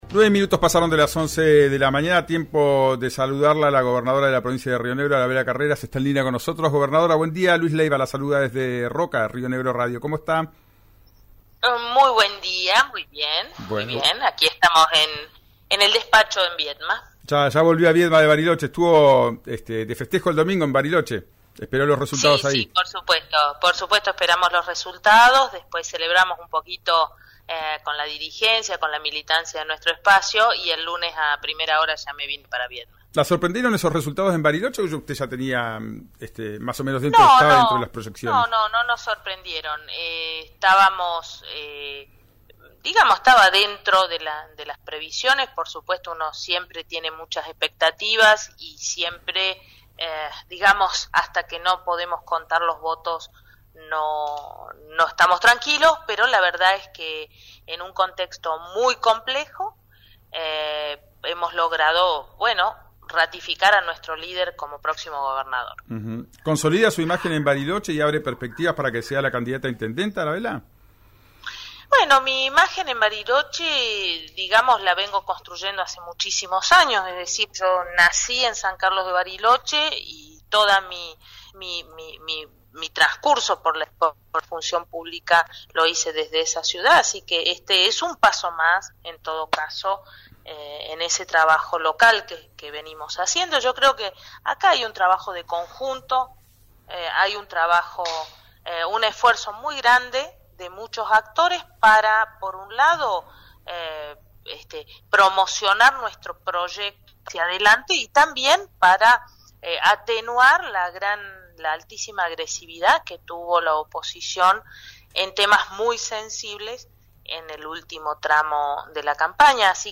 Arabela Carreras, quien seguirá al mando de la provincia hasta diciembre, habló con RÍO NEGRO RADIO y tocó varios temas importantes respecto este período de «transición» donde se apelará al «fuerte diálogo».